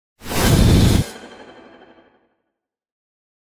mandrake fvtt13data/Data/modules/psfx/library/ranged-magic/generic/missile/001